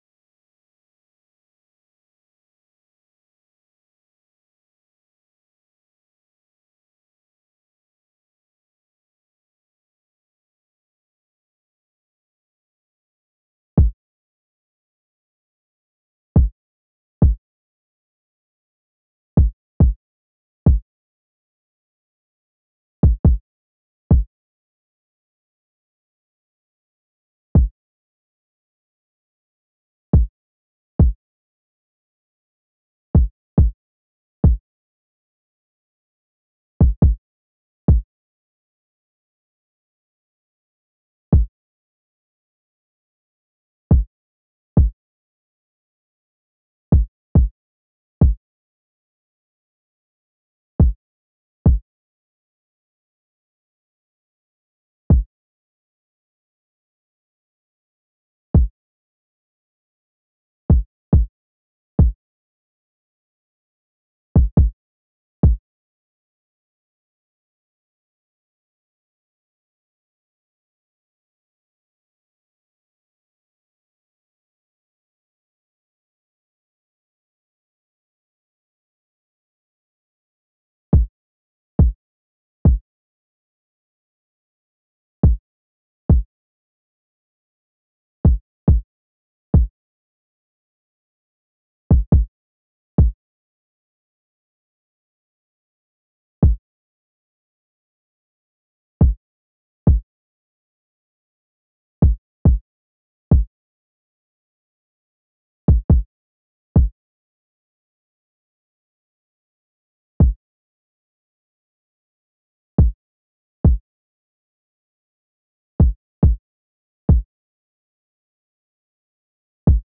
kits/808 Melo & Axl/Kicks/GATTI_KICK.wav at main
GATTI_KICK.wav